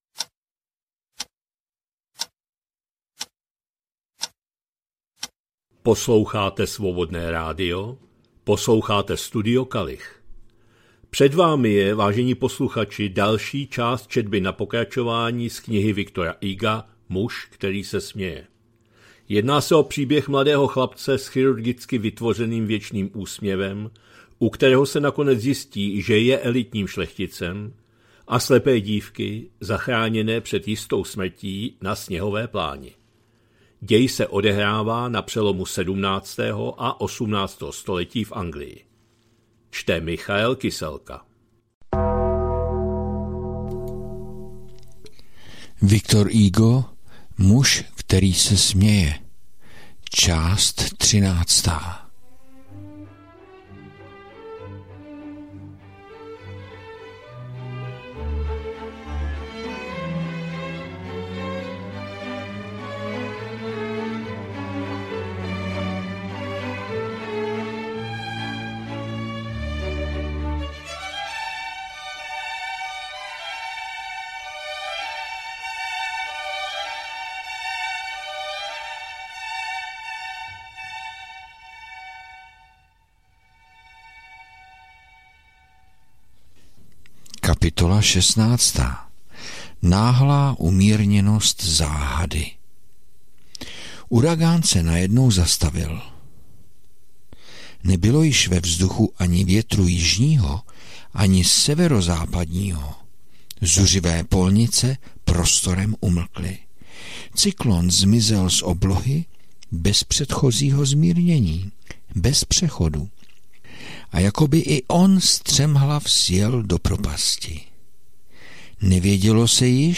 2025-06-02 – Studio Kalich – Muž který se směje, V. Hugo, část 13., četba na pokračování